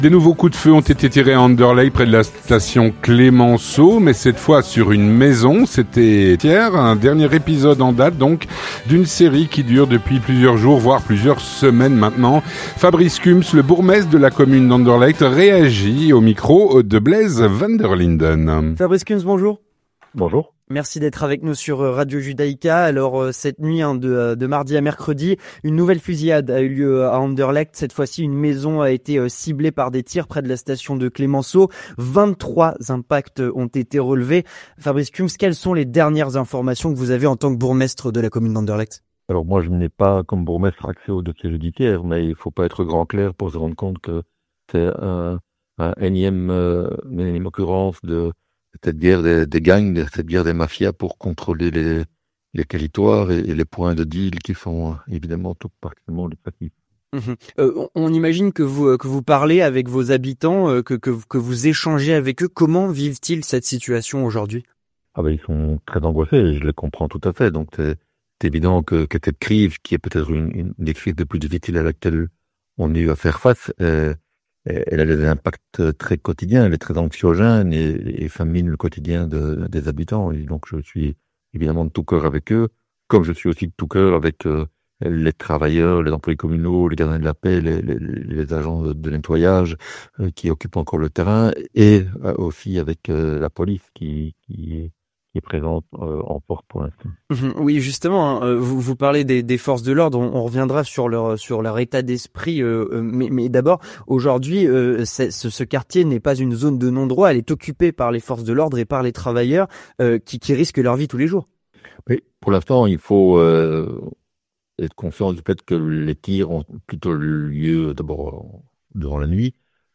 Avec Fabrice Cumps, bourgmestre de la commune d'Anderlecht.